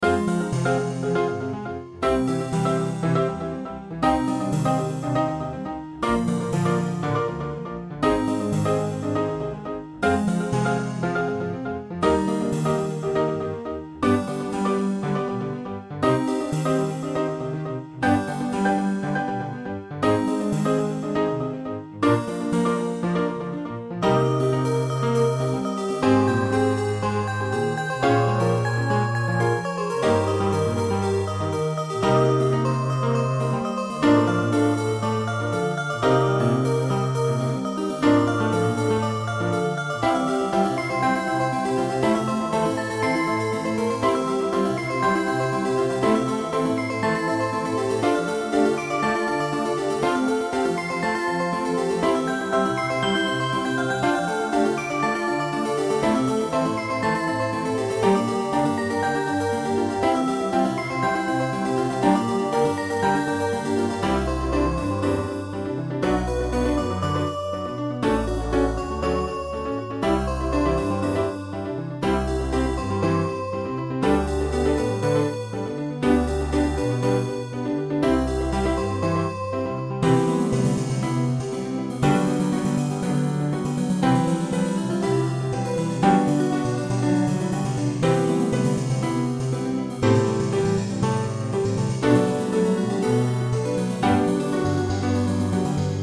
Fx5(ブライトネス)、ピアノ、コントラバス
BGM